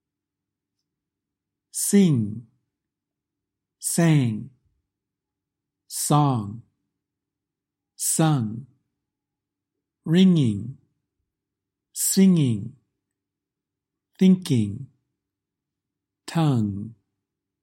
The /ŋ/ sound